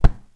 sfx_impact_pillow_02.wav